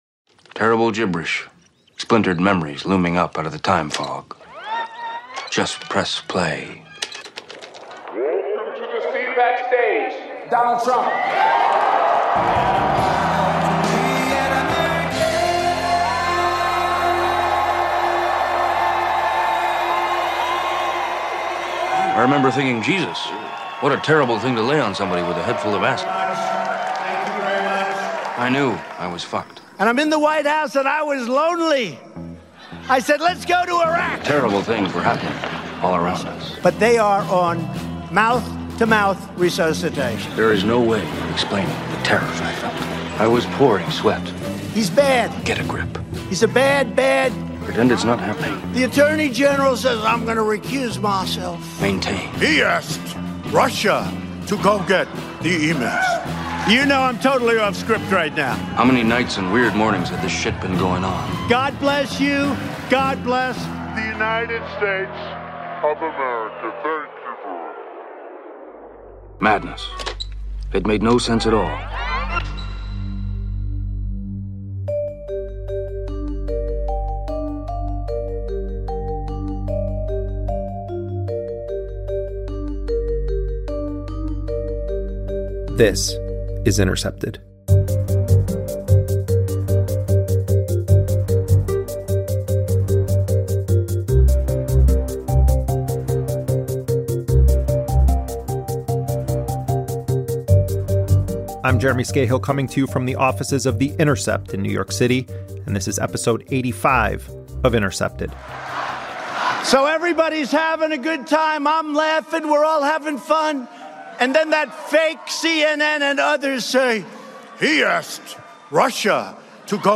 Journalist and Russiagate critic Aaron Maté presents his dissenting analysis, what he believes is behind the investigation, and how the scandal has distracted from other urgent issues.
We hear a new speech from professor Shoshana Zuboff , author of “ The Age of Surveillance Capitalism: The Fight for a Human Future at the New Frontier of Power. ”